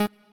left-synth_chord_last72.ogg